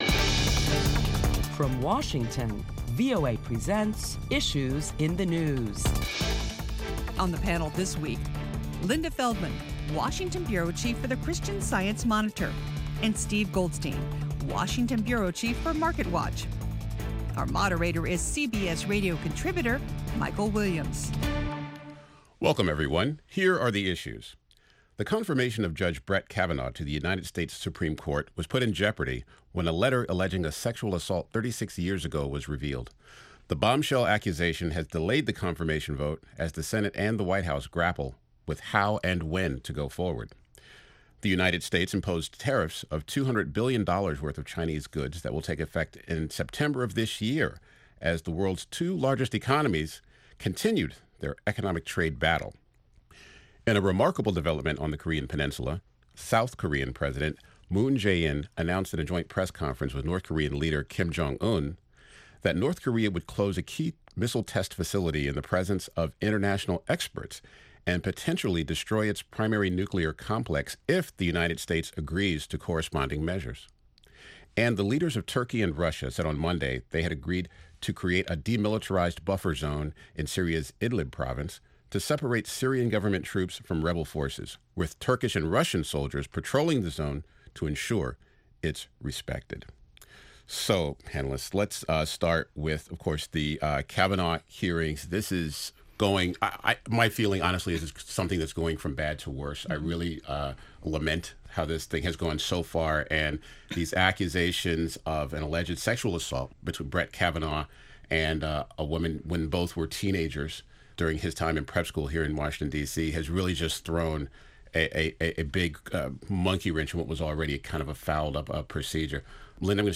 Listen in as top Washington correspondents discuss the latest on Brett Kavanaugh's Supreme Court confirmation hearings in light of a woman's accusation that he assaulted her while they were in high school - 36 years ago.